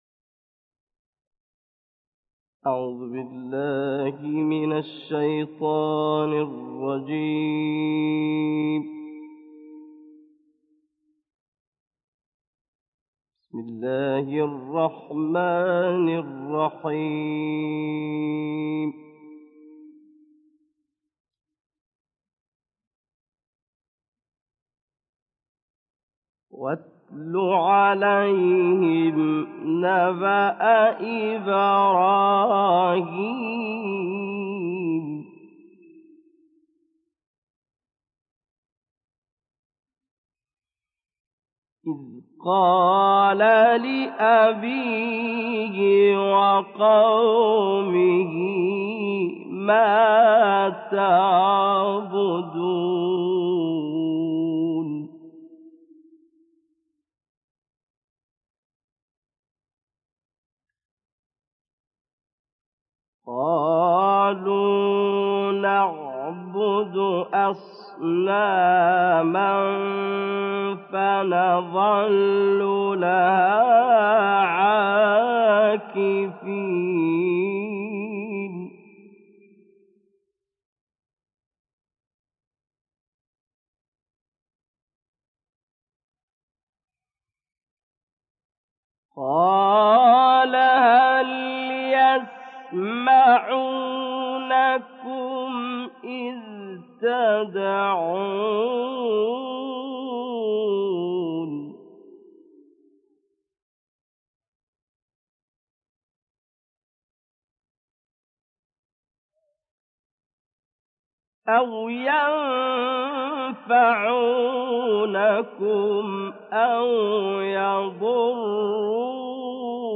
دانلود قرائت سوره های الشوری آیات 69 تا 90 ، النازعات 26 تا آخر ، شمس و انفطار - استاد محمد الیثی